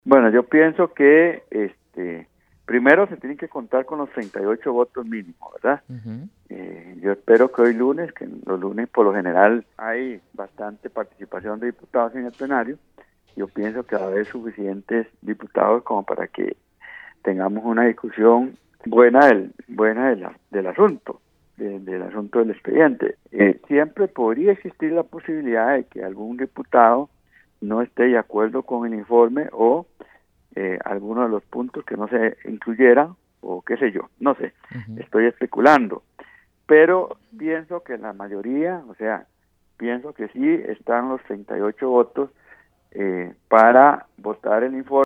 El diputado del Partido Acción Ciudadana (PAC) y presidente de dicha comisión, Marvin Atencio, explicó la dinámica que se llevará a cabo hoy para la votación del informe.